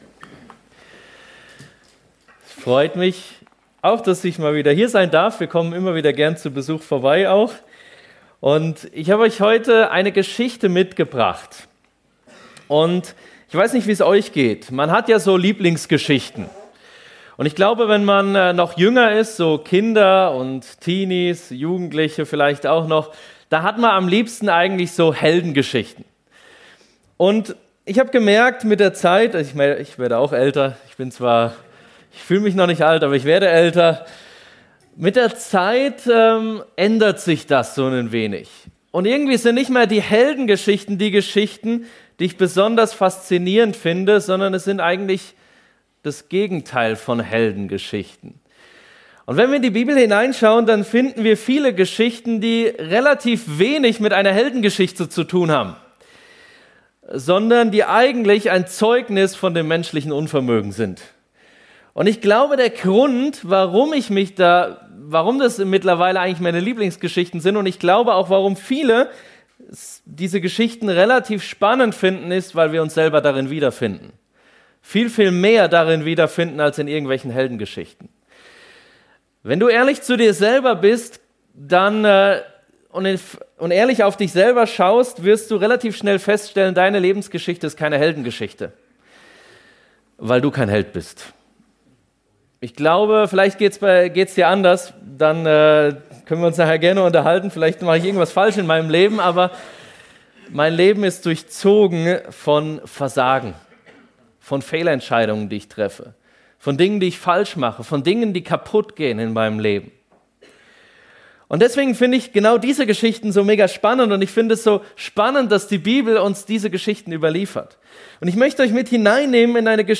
Predigten der Freien Evangelischen Gemeinde Sumiswald als Podcast.
FEG Sumiswald - Predigten